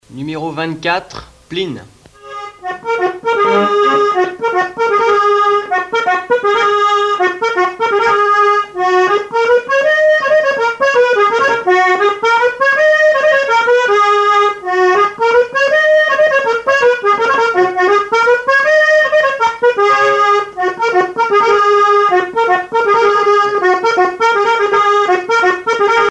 Plinn
danse : plinn
Pièce musicale éditée